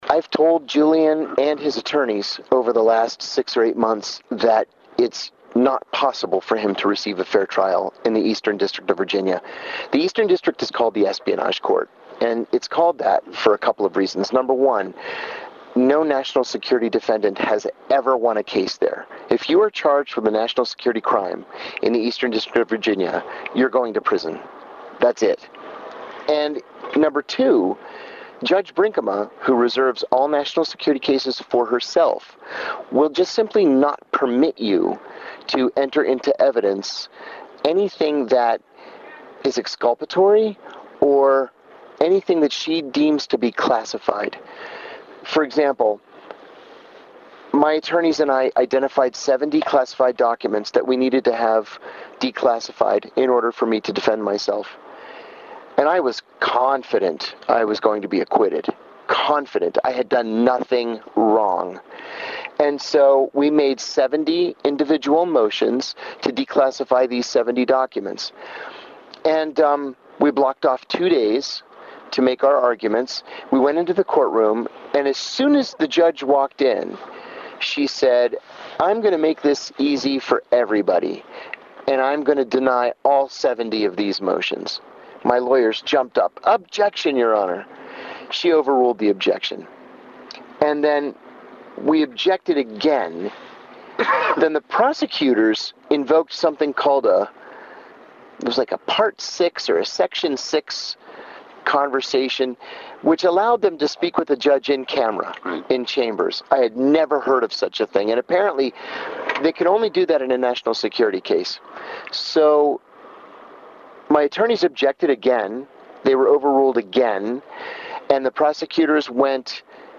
In-Depth Interview: John Kiriakou and Ai Wei Wei Talk About Alcatraz Film, Assange, and more
Kirkiakou and Ai share their own prison experiences, and offer strong support for the recently arrested Julian Assange, and for Chelsea Manning, who appears in the movie and has been re-incarcerated for refusing to testify before the Assange grand jury.